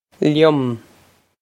Liom lyum
This is an approximate phonetic pronunciation of the phrase.